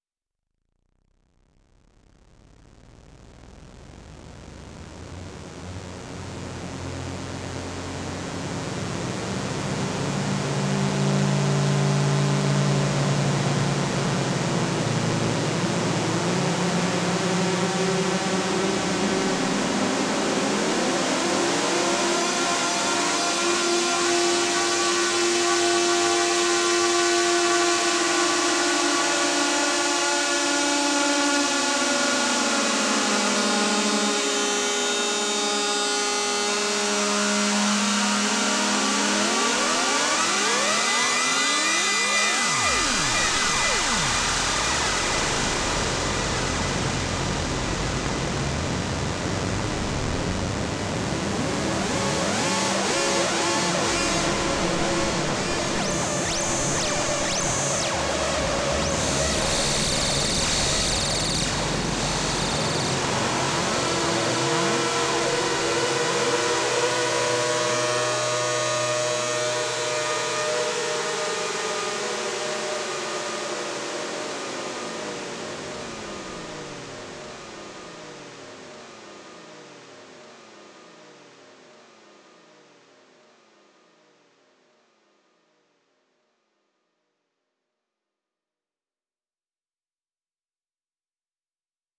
All recording were created using the Buchla Red Panel 100 Series Eurorack modules.